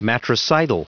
Prononciation du mot matricidal en anglais (fichier audio)
Prononciation du mot : matricidal